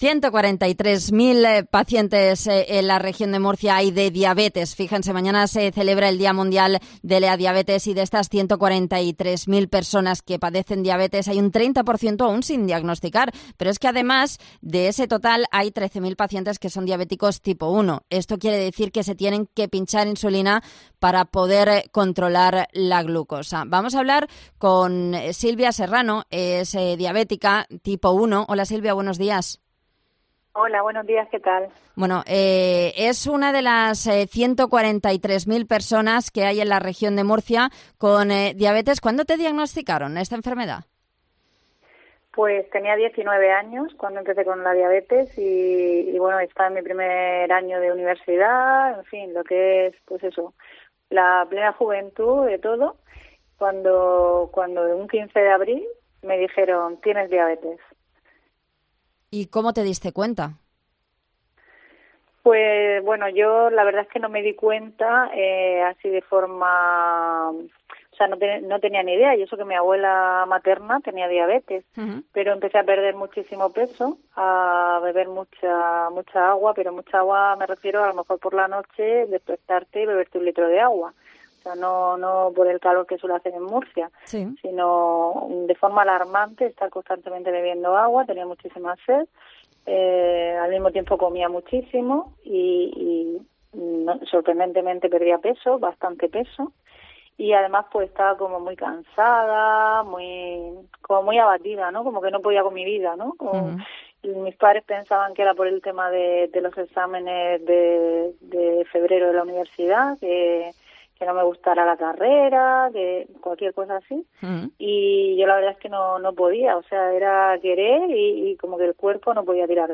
Hoy ha contado su experiencia en COPE Murcia .